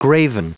Prononciation du mot graven en anglais (fichier audio)
Prononciation du mot : graven